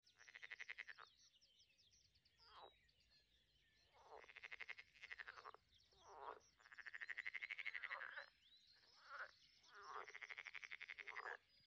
The Sound Of Nature 🌿🐸 Sound Effects Free Download